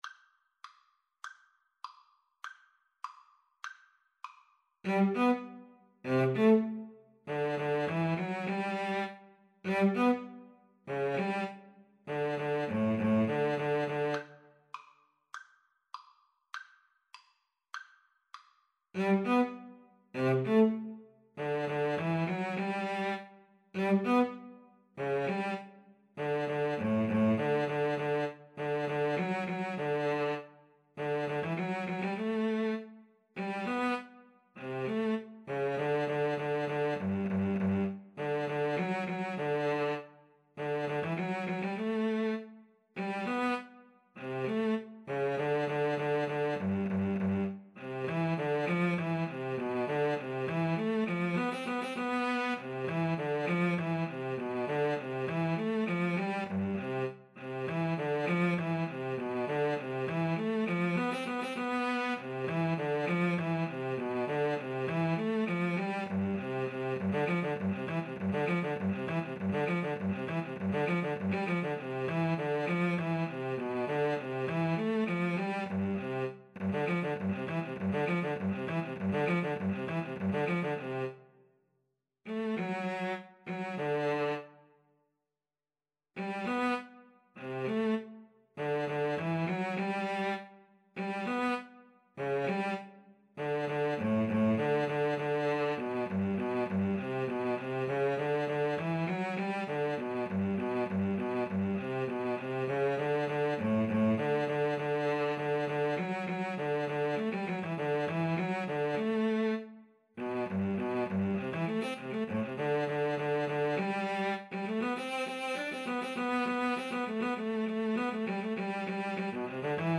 2/4 (View more 2/4 Music)